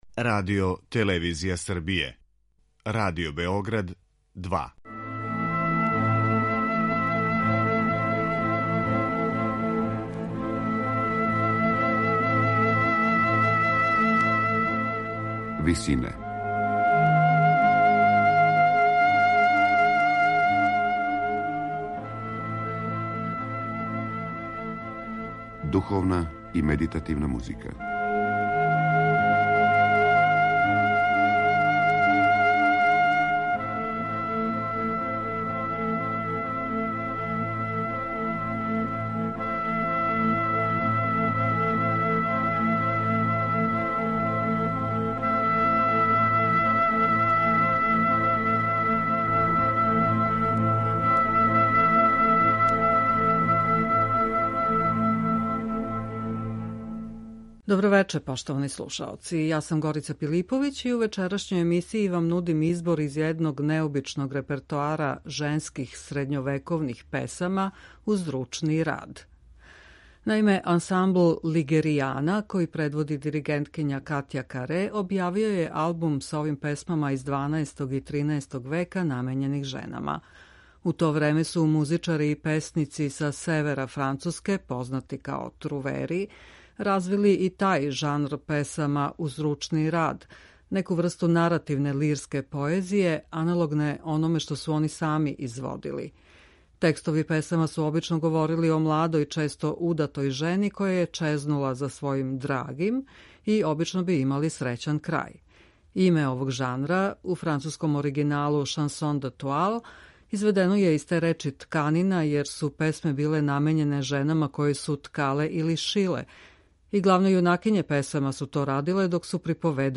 женских средњовековних песама уз ручни рад